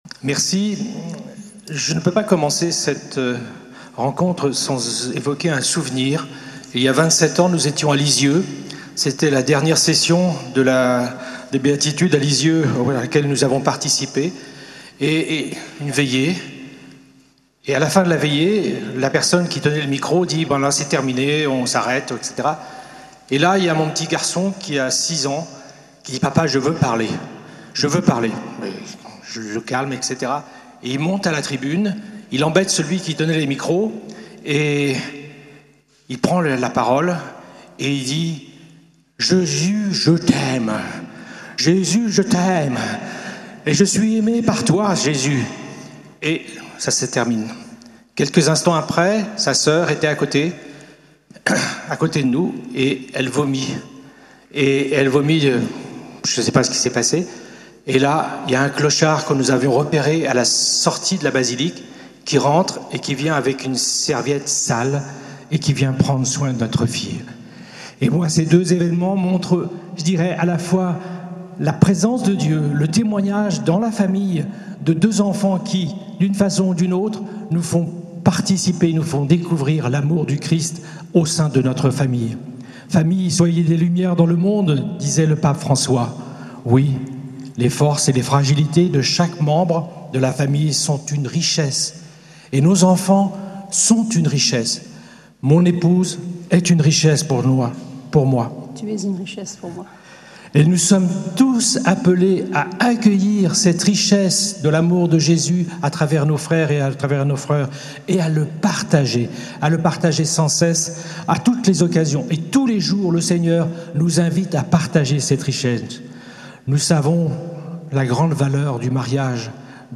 Lourdes, Pèlerinage avec la Cté des Béatitudes